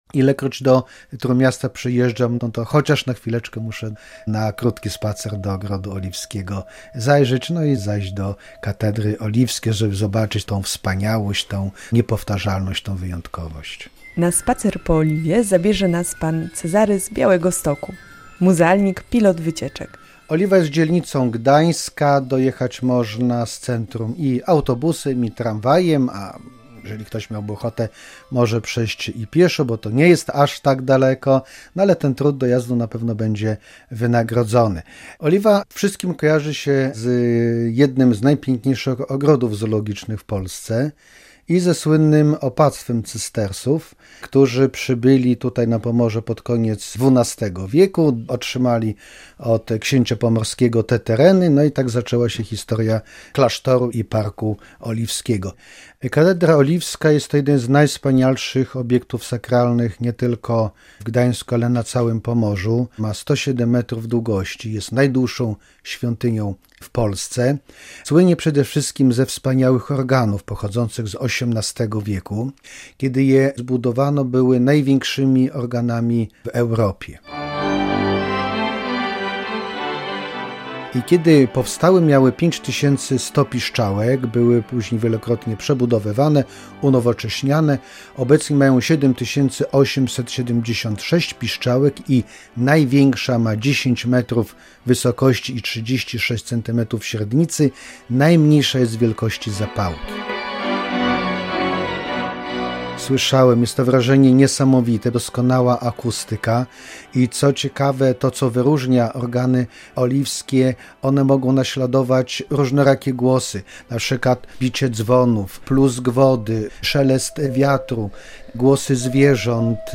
Tym razem udamy się w podróż do gdańskiej Oliwy. Zajrzymy do tego urokliwego zakątka miasta, by posłuchać słynnych oliwskich organów, a potem odpocząć w Parku Oliwskim w cieniu alei lipowej, czy poszeptać w słynnych Grotach Szeptów.